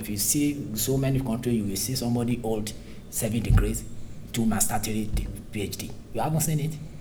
S1 = Bruneian female S3 = Nigerian male Context : S3 is talking about the level of qualifications people in society have, and whether getting extra university degrees is useful.
Intended Words: hold , seven , two , three Heard as: old , same with , to , theory Discussion: The absence of an initial [h] on hold seems to start the problem. In addition, three is not said very clearly, so S1 guessed that maybe it was theory .